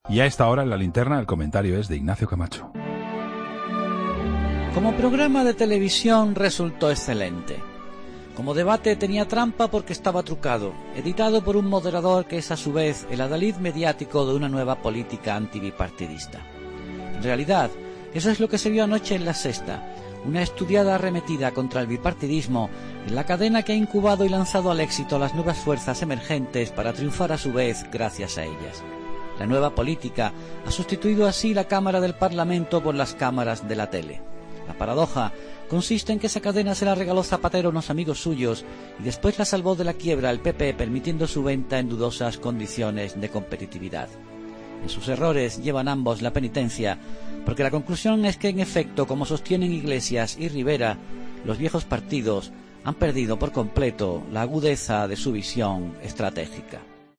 Ignacio Camacho dedica su comentario al debate emitido la noche del domingo en televisión, entre Pablo Iglesias y Albert Rivera.